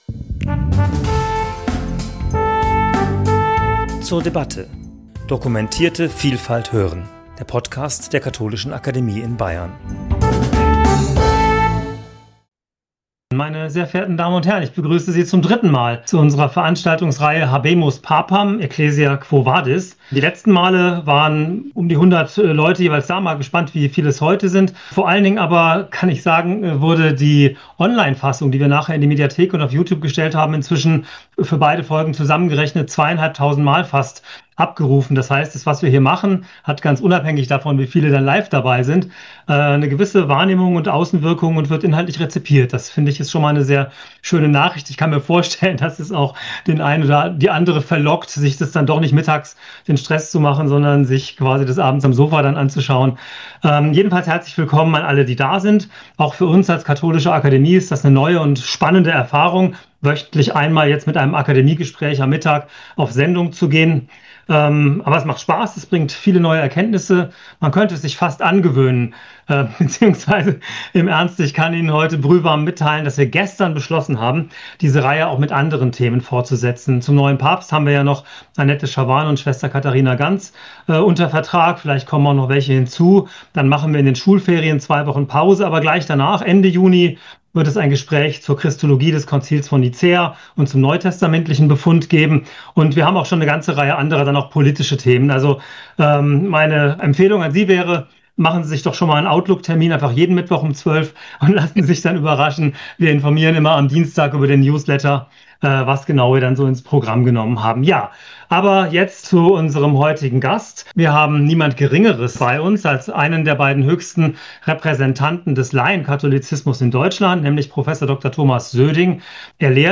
Gespräch zum Thema 'Habemus papam! Ecclesia, quo vadis?', Folge 3 ~ zur debatte Podcast